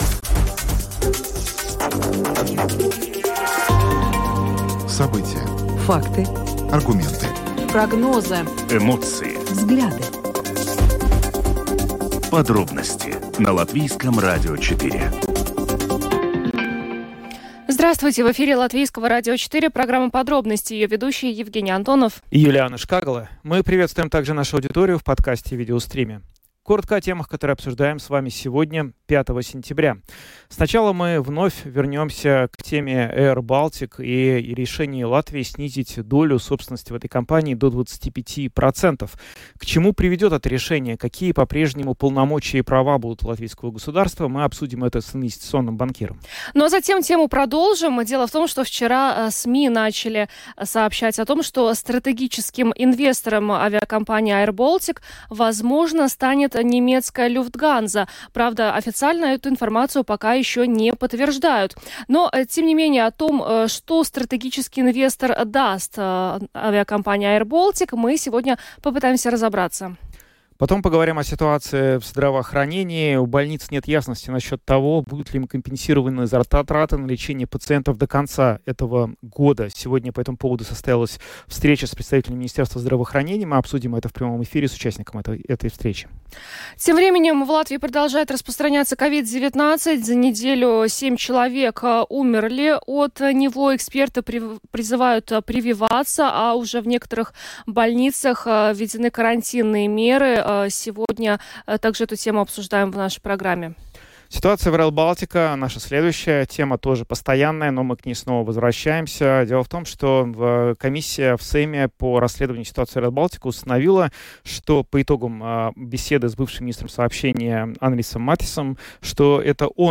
Самые важные темы дня и актуальные интервью, взгляд со стороны и комментарии от первоисточников - слушайте каждый день в программе “Подробности”.